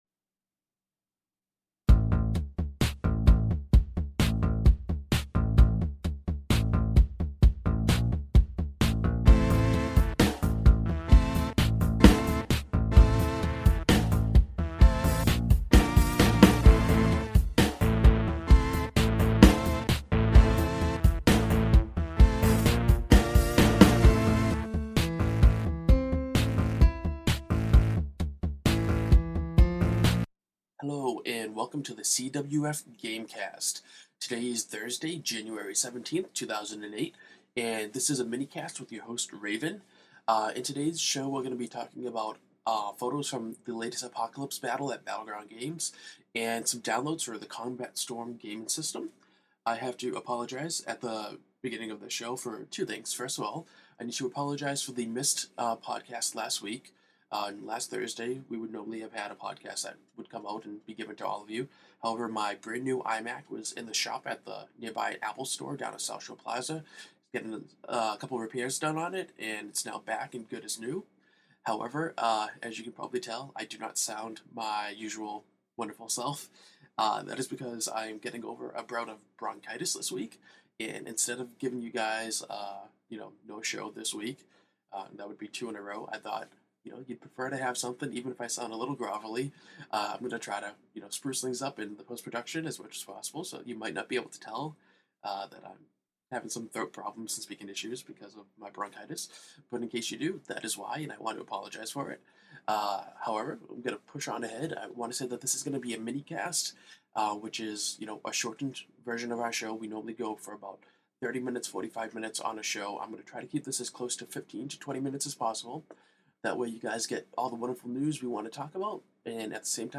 (Electronic)